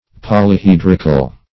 Search Result for " polyhedrical" : The Collaborative International Dictionary of English v.0.48: Polyhedral \Pol`y*he"dral\, Polyhedrical \Pol`y*hed"ric*al\, a. [See Polyhedron .]
polyhedrical.mp3